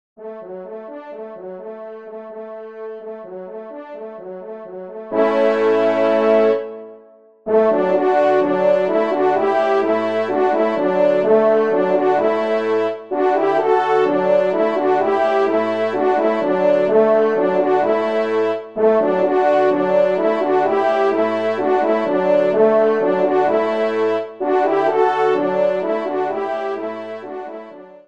TON SIMPLE :
ENSEMBLE